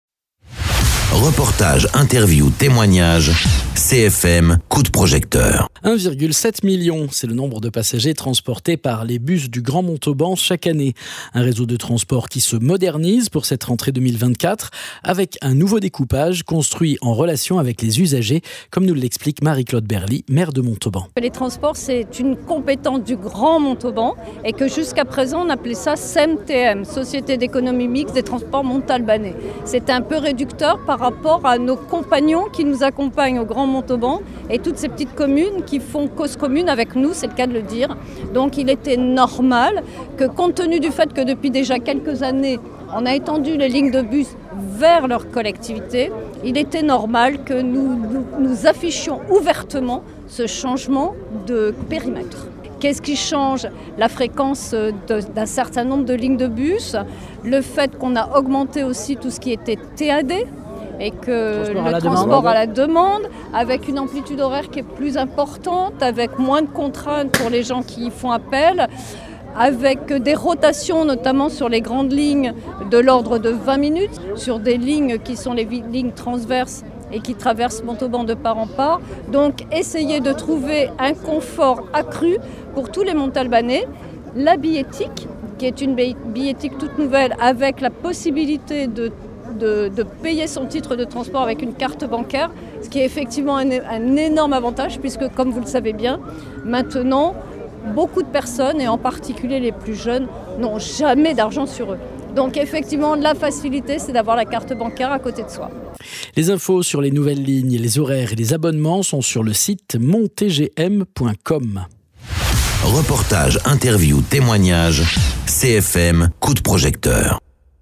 1,7 million, c’est le nombre de passagers transportés par les bus du grand Montauban chaque année. Un réseau de transport qui se modernise pour cette rentrée 2024 avec un nouveau découpage construit en relation avec les usagers comme nous l’explique Marie-Claude Berly, Maire de Montauban…
Interviews